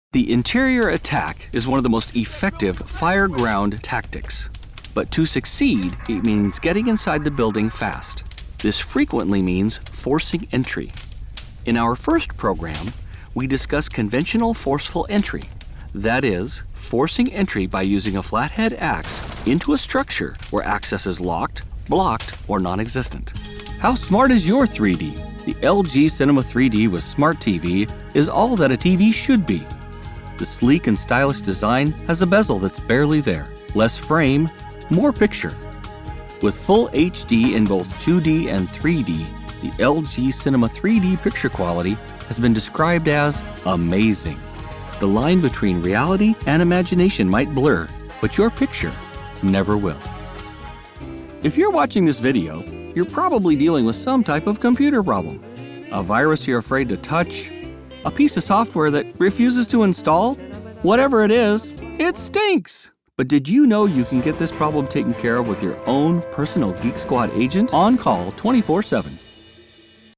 Male
English (North American)
Yng Adult (18-29), Adult (30-50)
Narration
1204NARRATION_DEMO_MASTER.mp3